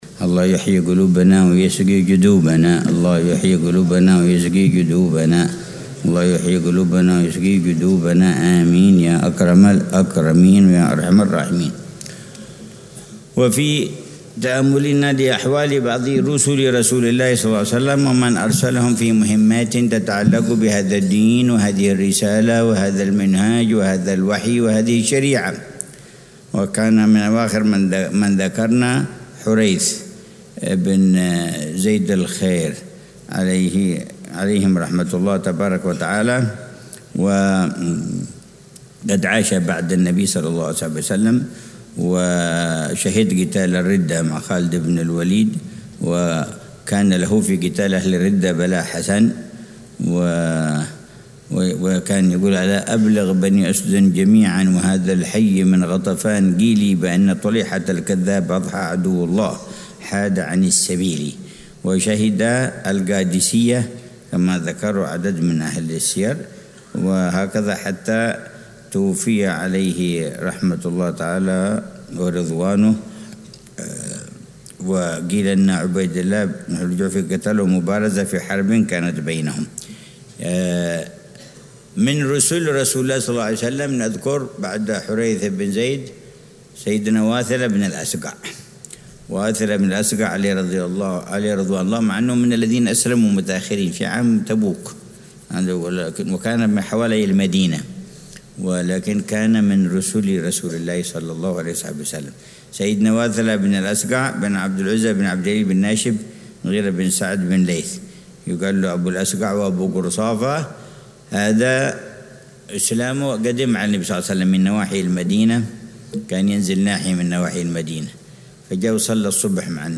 درس السيرة النبوية - رُسُل رسول الله ﷺ: حريث بن زيد - 4 - واثلة بن الأسقع - 1